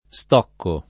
stocco [ S t 0 kko ], stocchi